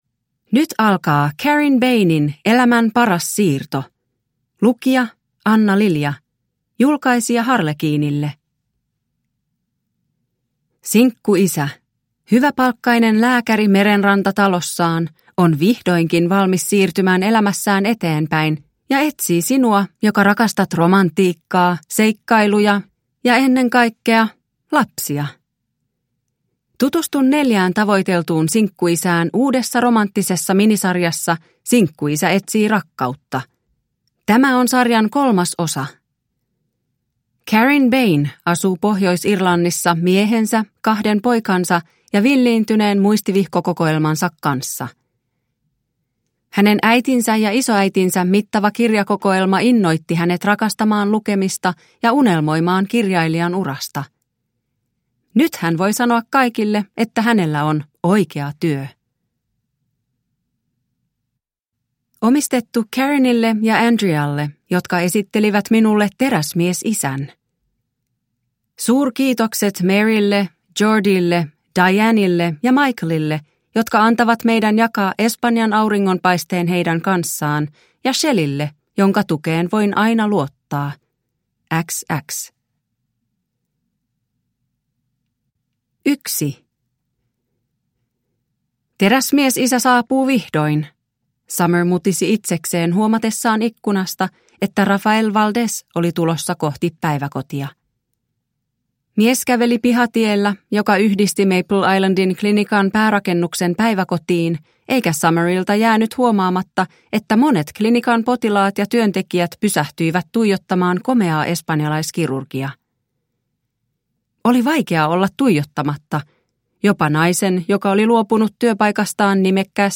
Elämän paras siirto – Ljudbok – Laddas ner